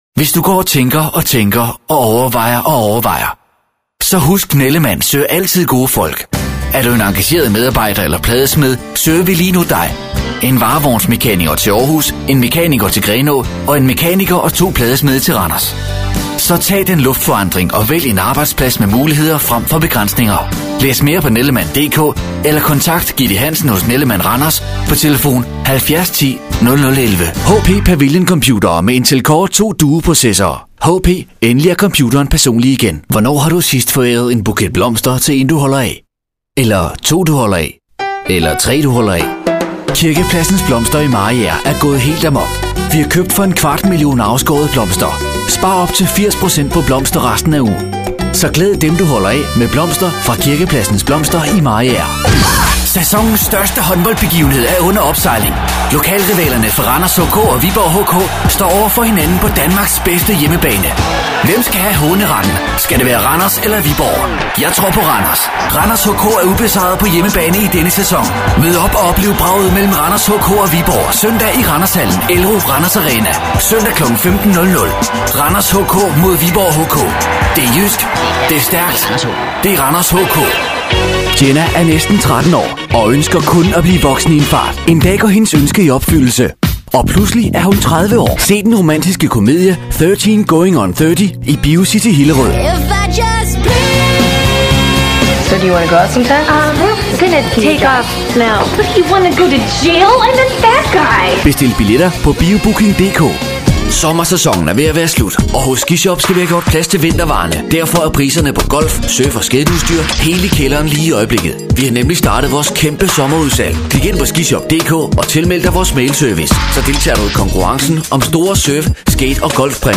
Professioneller dänischer Sprecher für TV/Rundfunk/Industrie.
Sprecher dänisch
Sprechprobe: Industrie (Muttersprache):